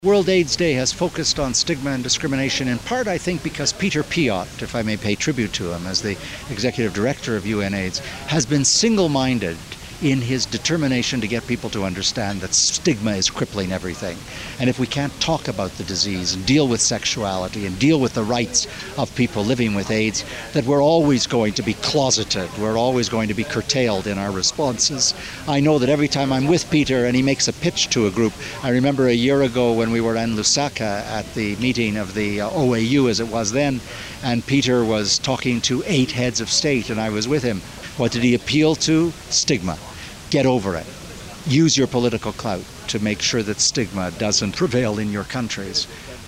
?Audio messages
?????UN Special Envoy for HIV/AIDS